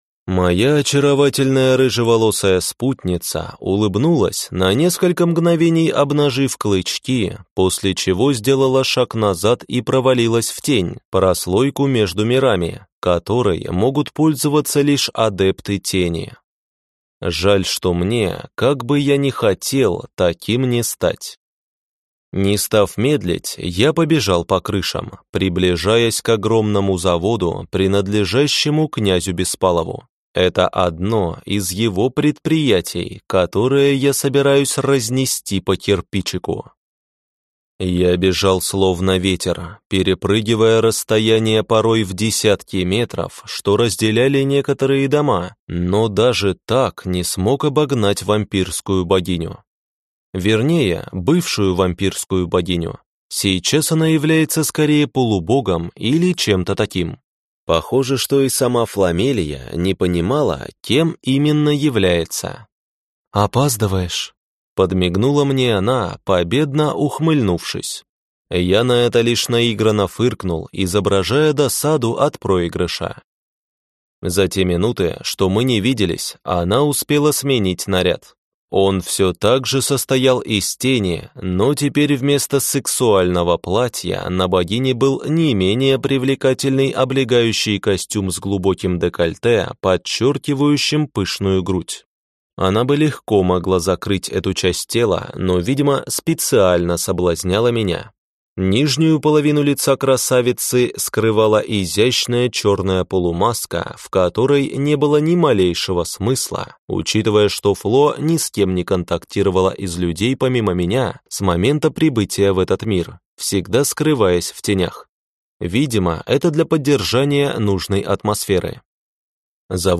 Аудиокнига Гнев Империи. Книга 2 | Библиотека аудиокниг